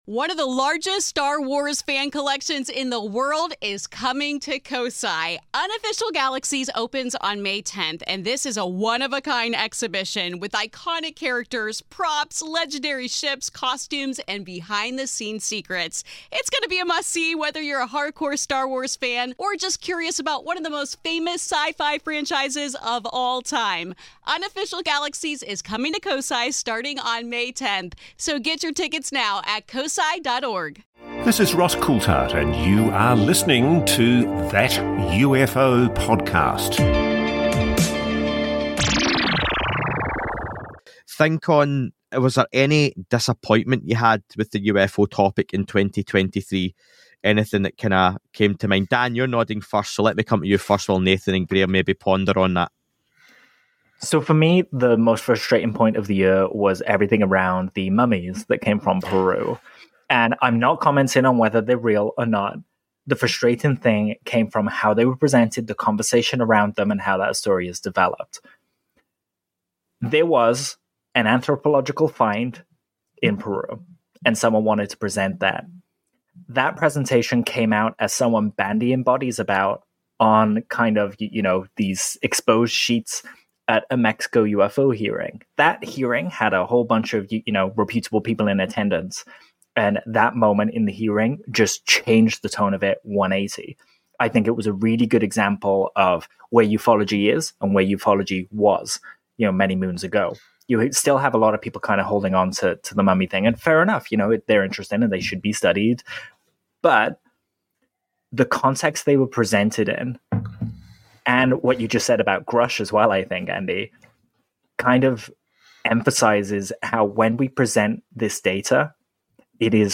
Roundtable; 2023 UFO News Retrospective, Pt.2